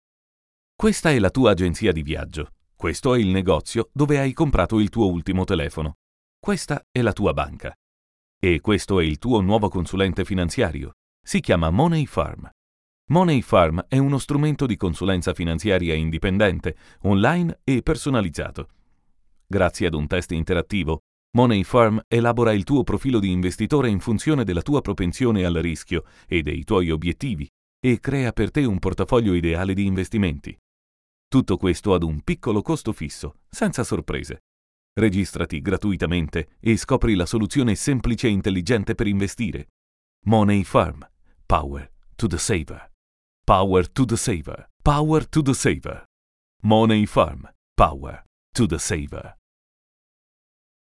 Sprechprobe: Industrie (Muttersprache):
Italian Native Voice Over with perfect pronunciation. Warm silky friendly Versatile Character Native Agreeable Virile Agile Deep Young Adult